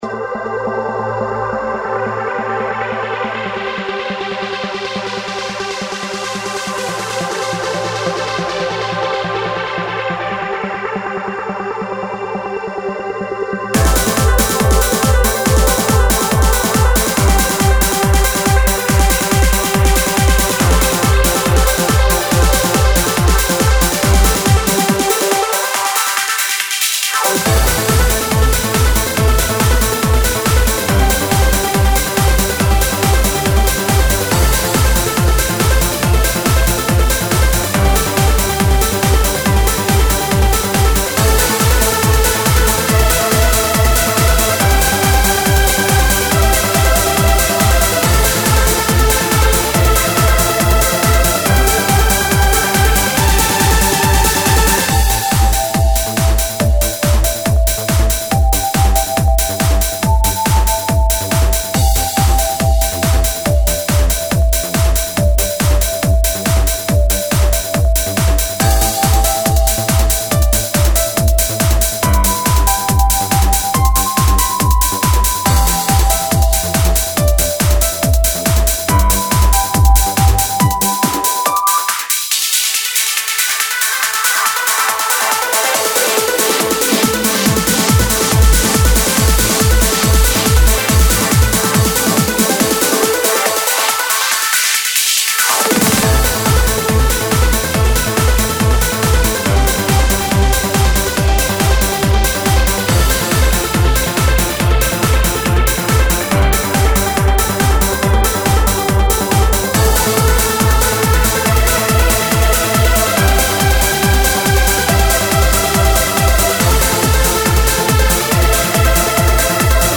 編曲家：テクノサウンド